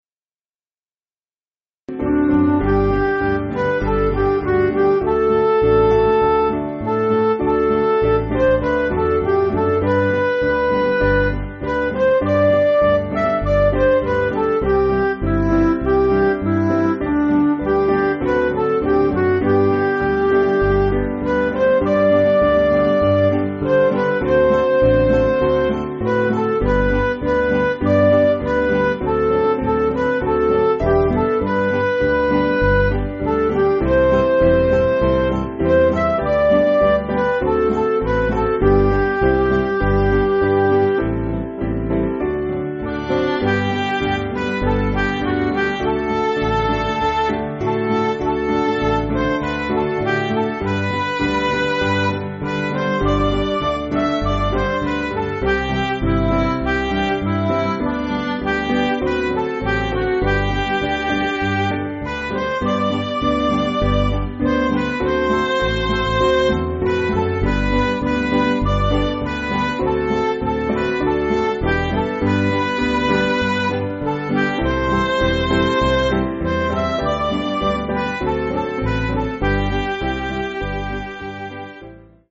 Piano & Instrumental
(CM)   4/G